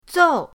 zou4.mp3